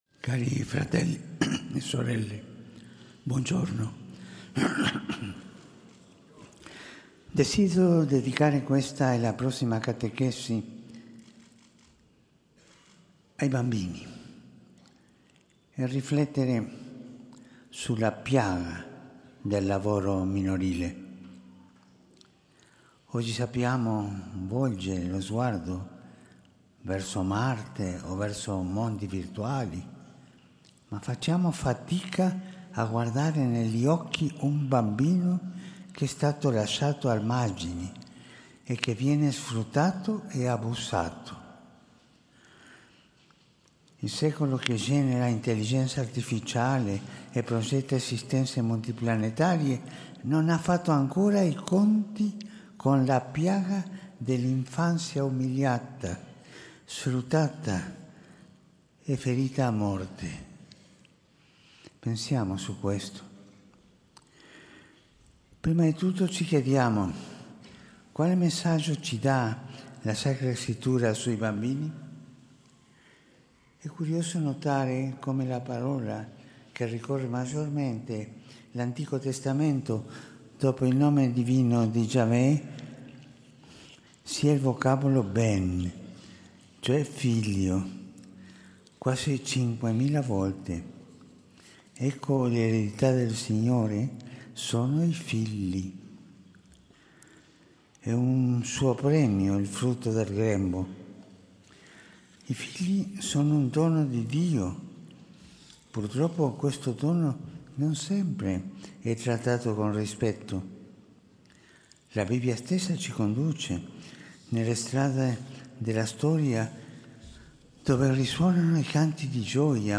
UDIENZA GENERALE di PAPA FRANCESCO
Aula Paolo VIMercoledì, 8 gennaio 2025